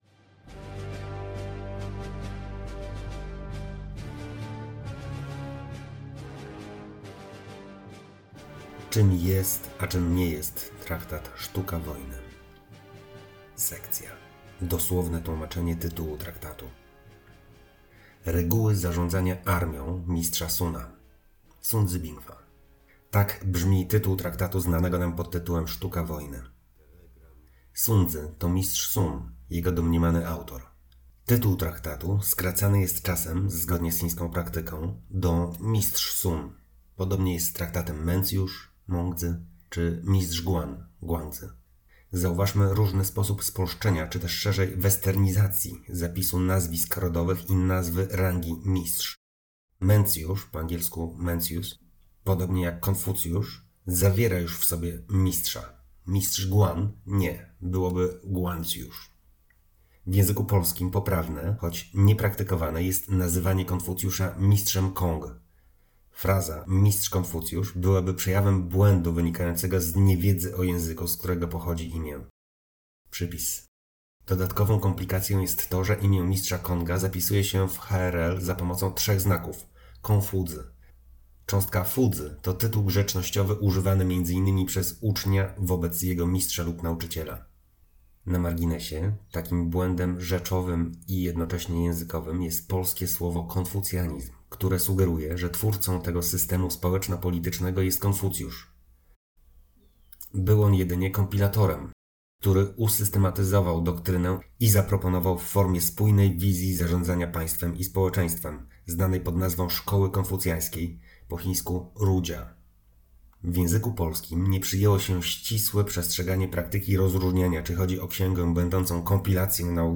Audiobook: Sun Zi i jego Sztuka wojny.